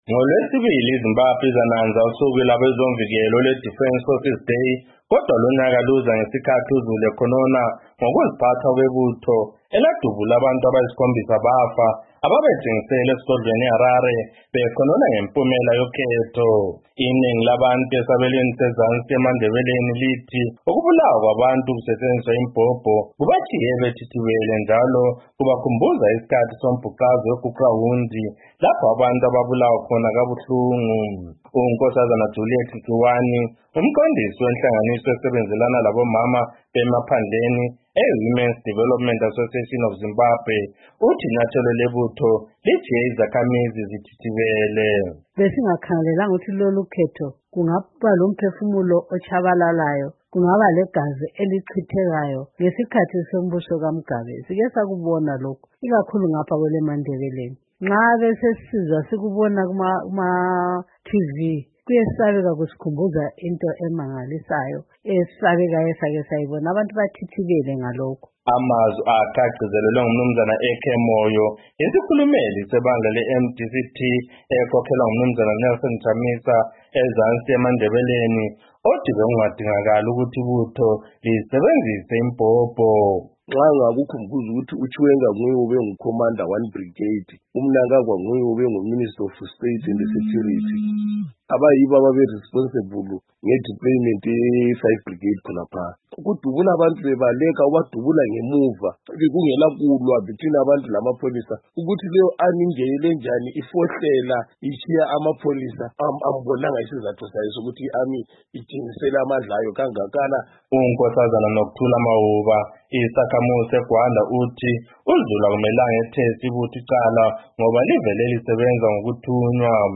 GWANDA, MATABELALAND SOUTH —